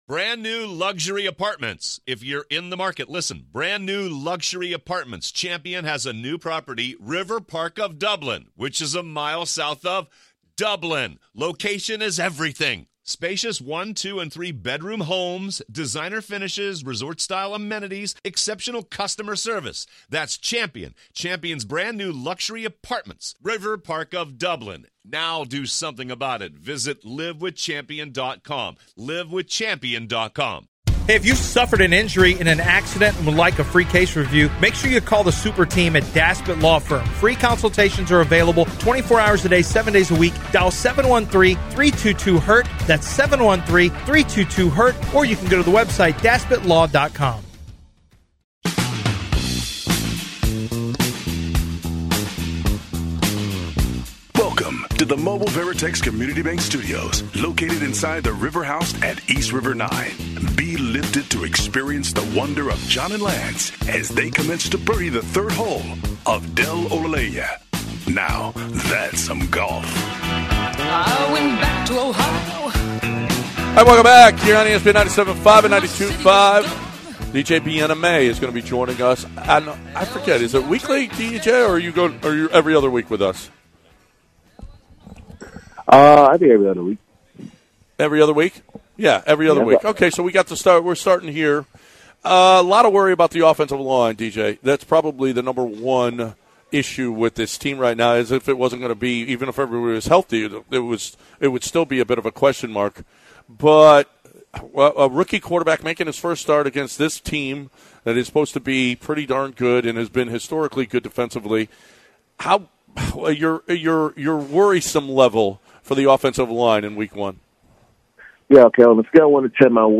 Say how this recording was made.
Live from East River 9